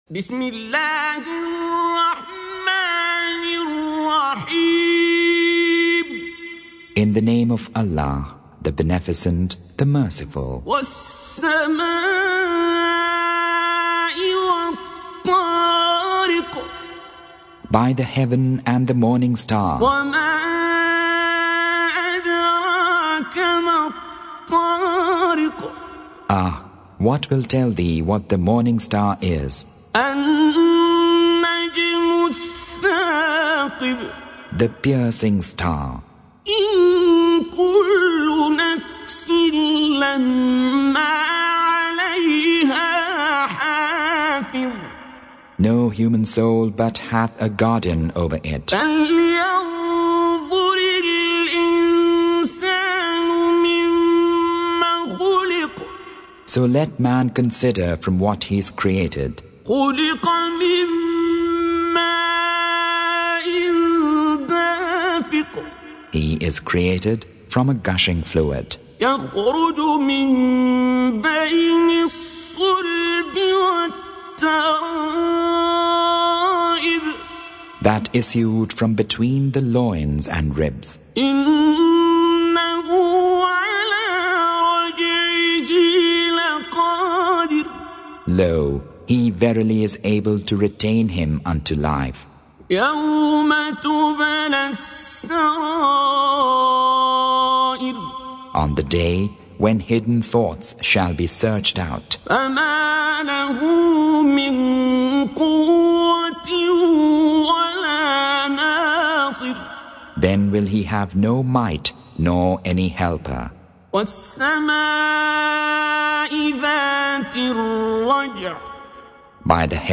Surah Sequence تتابع السورة Download Surah حمّل السورة Reciting Mutarjamah Translation Audio for 86. Surah At-T�riq سورة الطارق N.B *Surah Includes Al-Basmalah Reciters Sequents تتابع التلاوات Reciters Repeats تكرار التلاوات